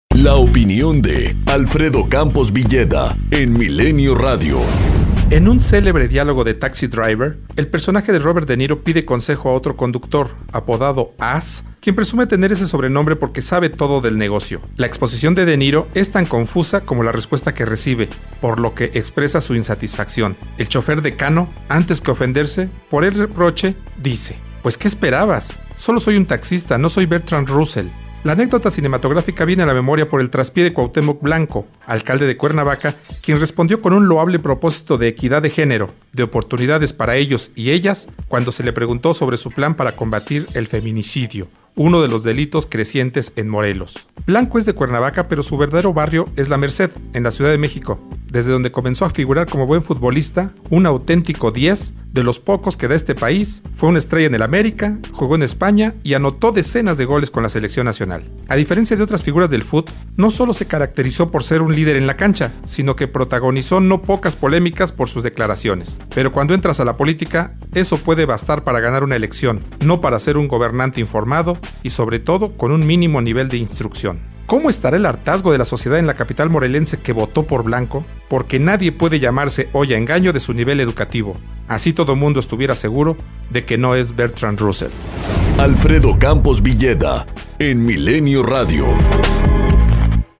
Comentario